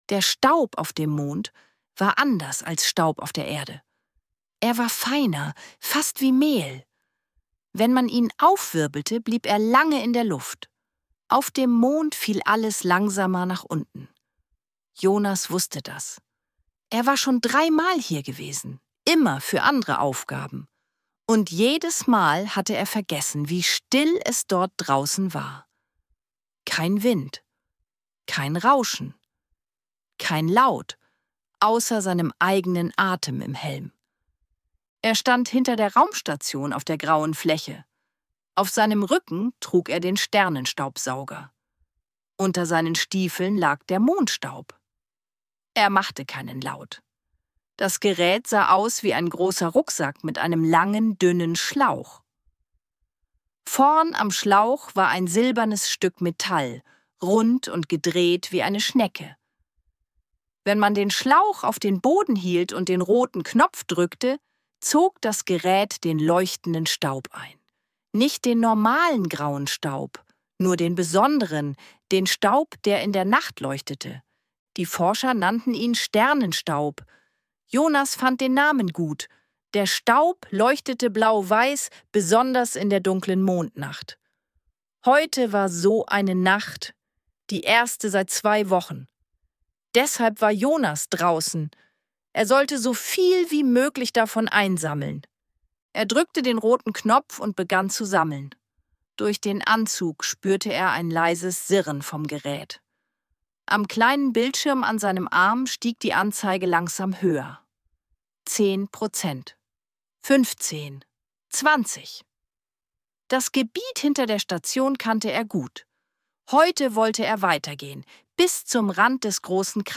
Eine ruhige Abenteuergeschichte für Kinder über Staunen, Entdeckung und ein geheimnisvolles Licht.